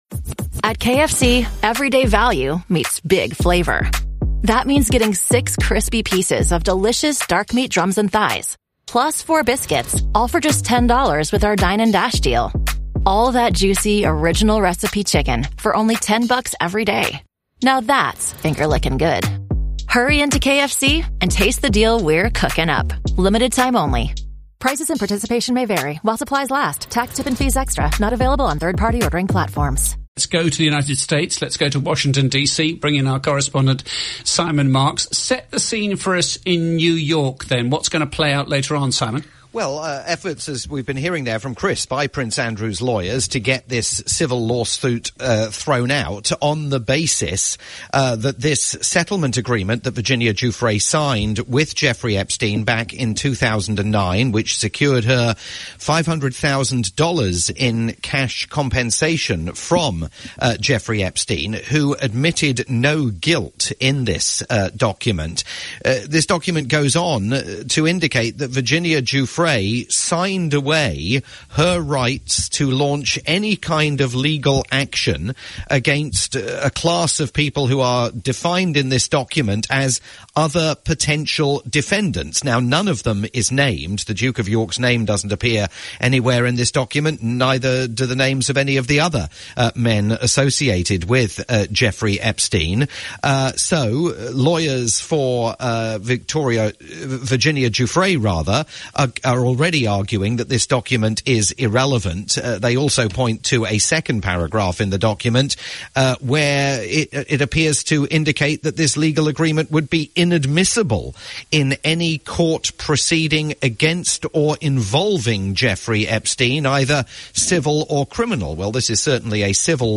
live roundup